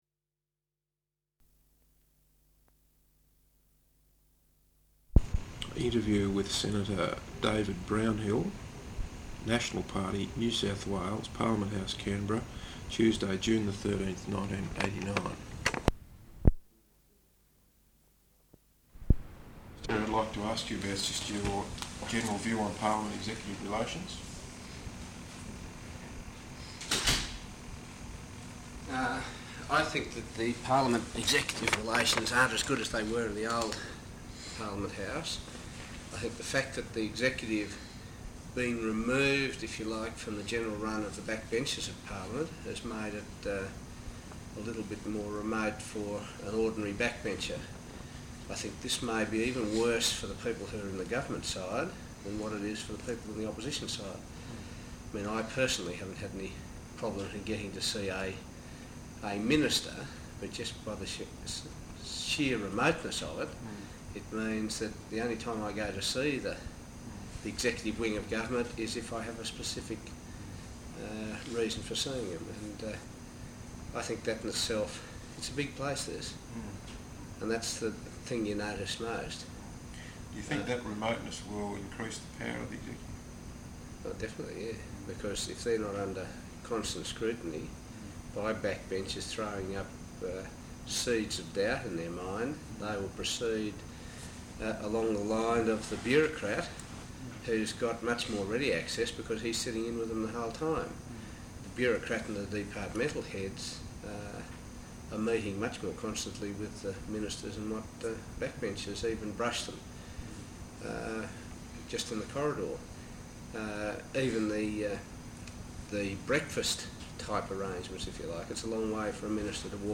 Interview with Senator David Brownhill, National Party Senator for New South Wales. Parliament House, Canberra, Tuesday June 13th, 1989.